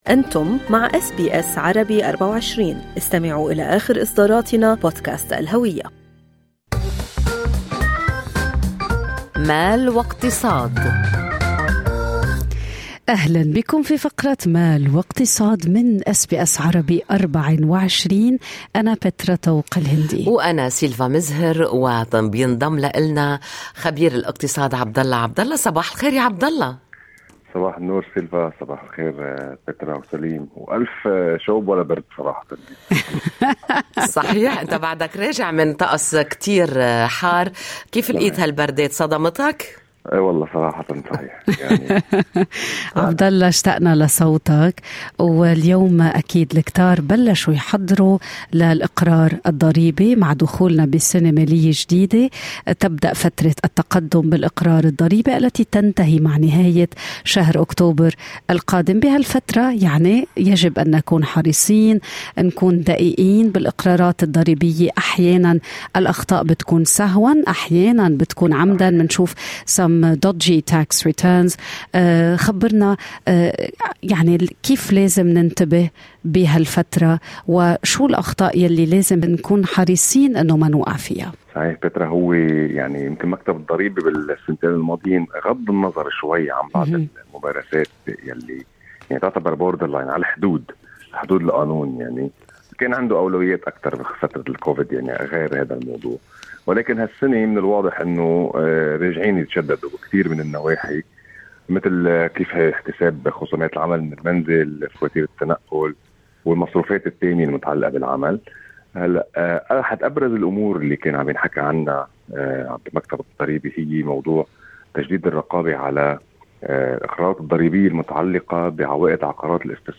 المحلل الاقتصادي